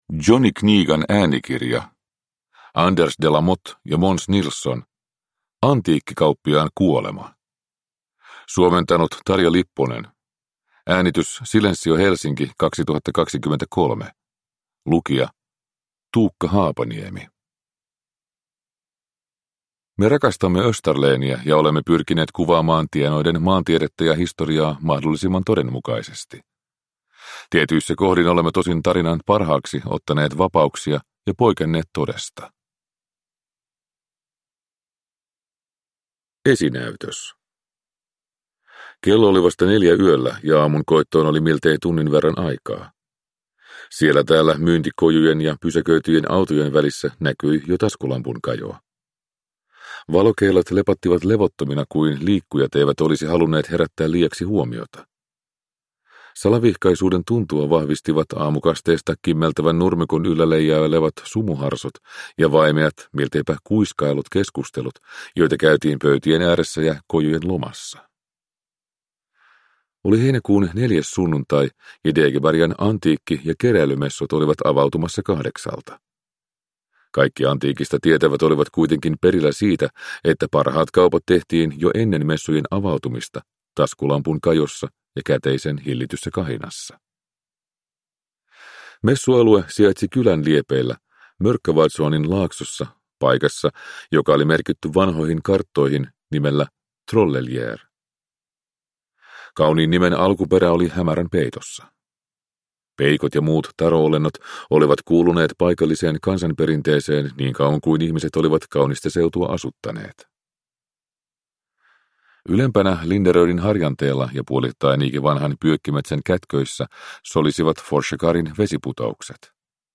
Antiikkikauppiaan kuolema – Ljudbok – Laddas ner